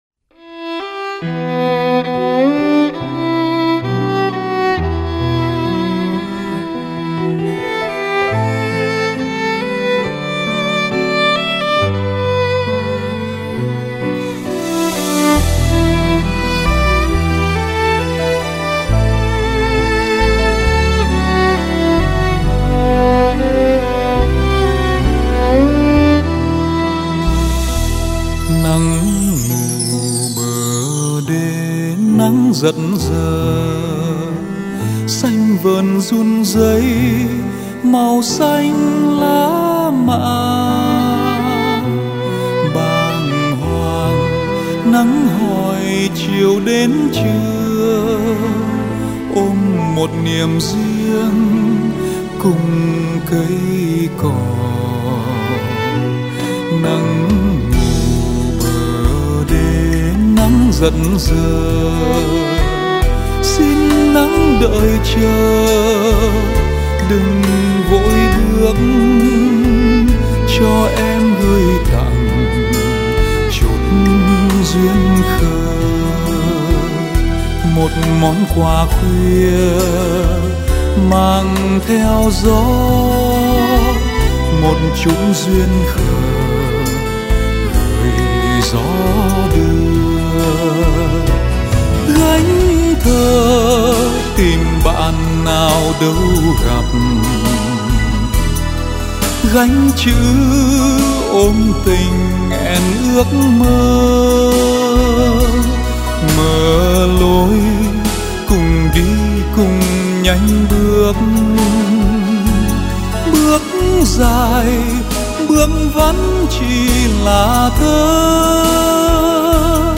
ca sĩ